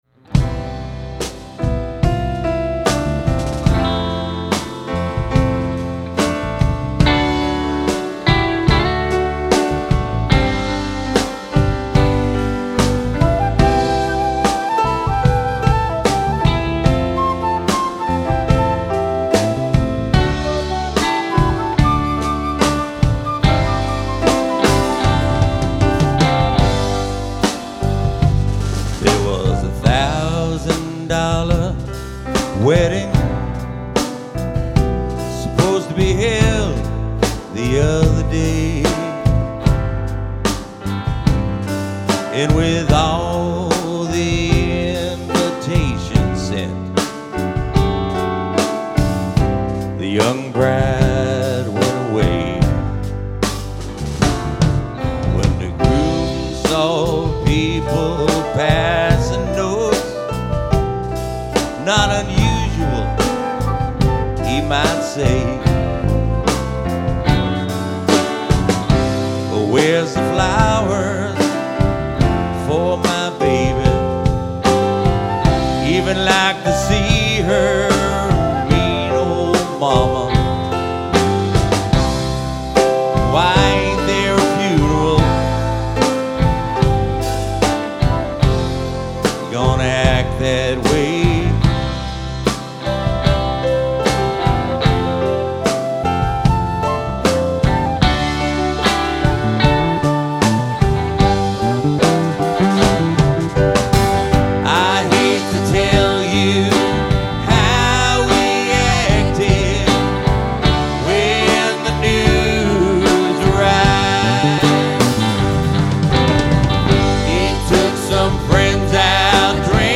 guitar vocals
keys vocals
drums
bass
sax